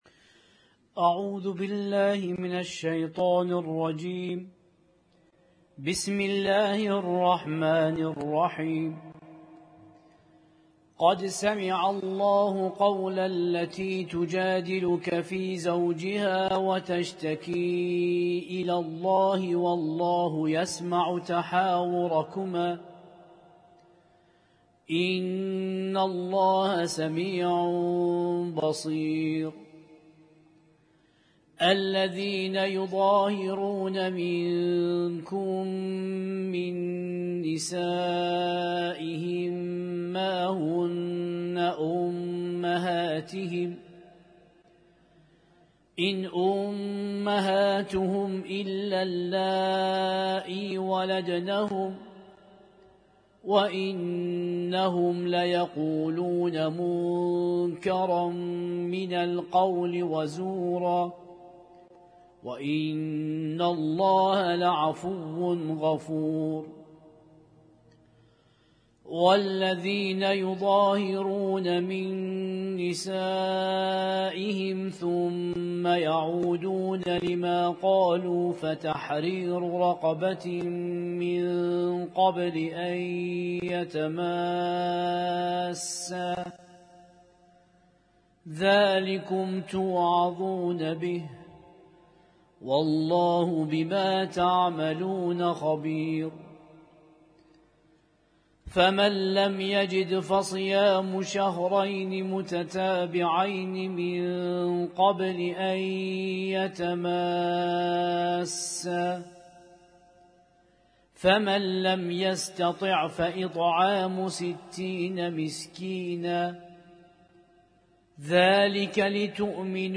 القارئ: القارئ